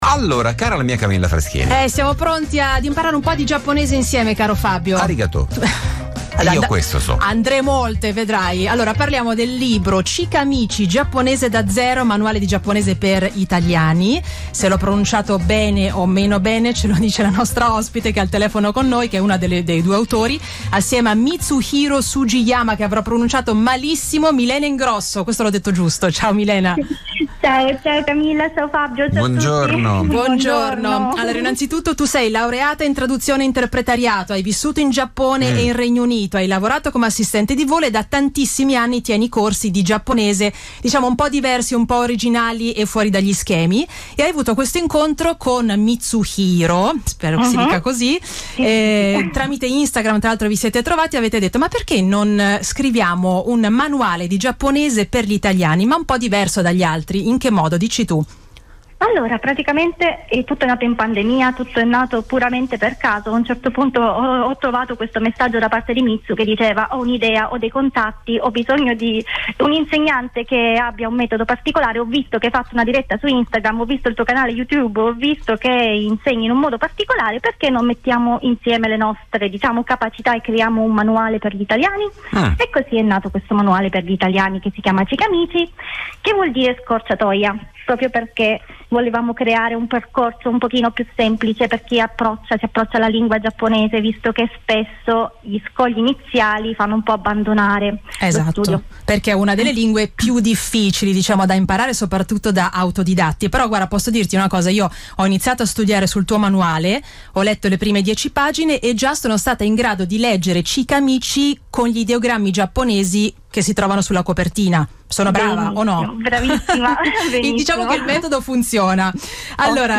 Intervista del 08/08/2022